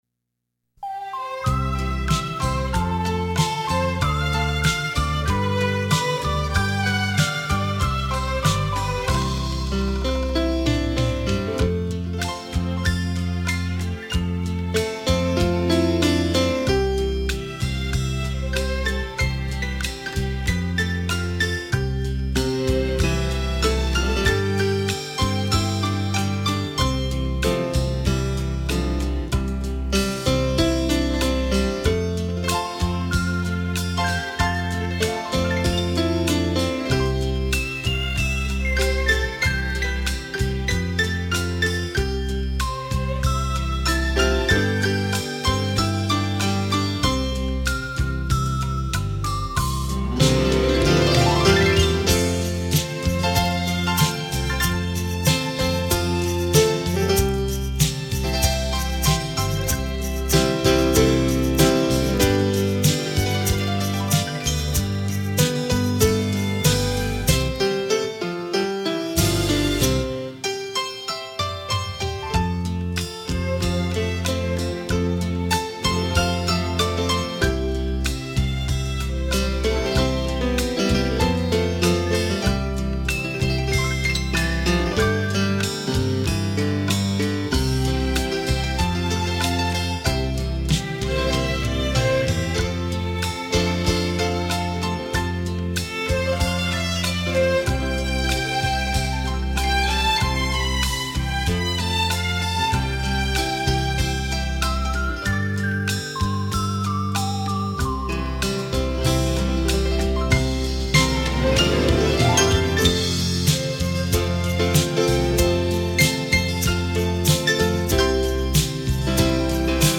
钢琴的琴键与小提琴的琴弦 交织出浪漫美丽的生活情趣